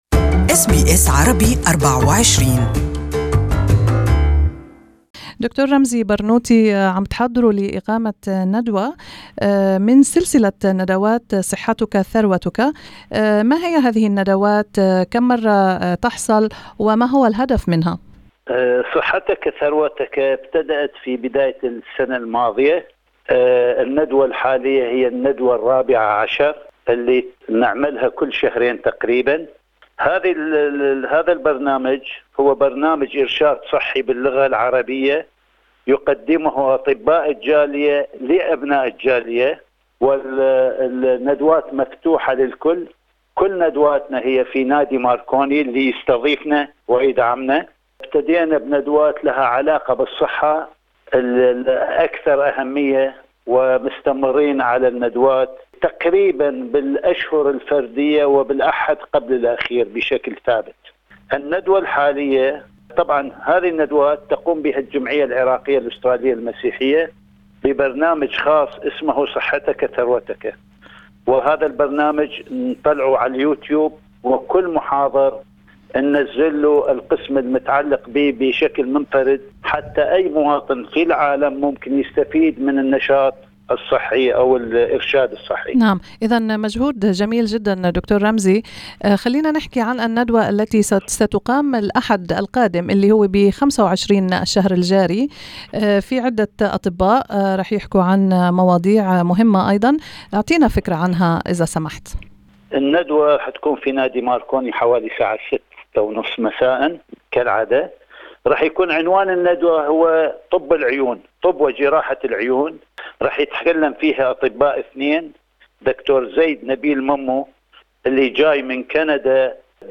أس بي أس عربي